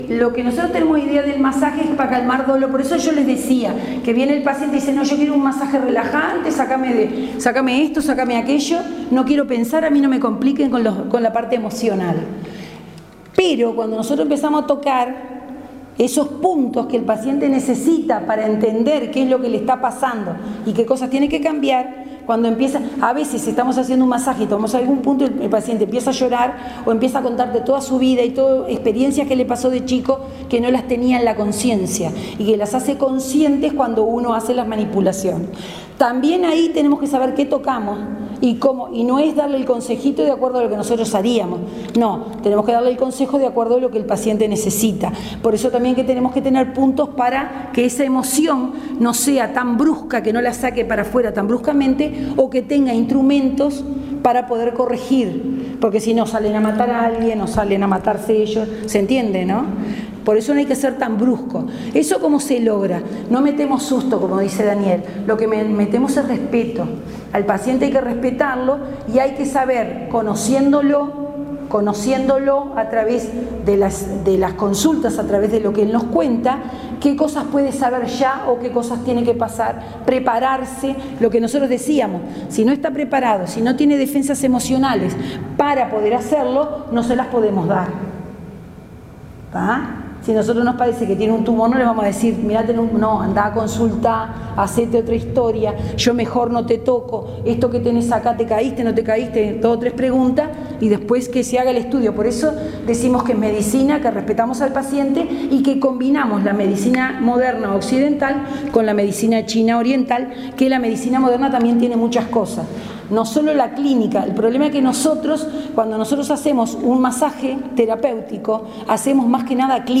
Charla n3_congreso actualizacion medicina.mp3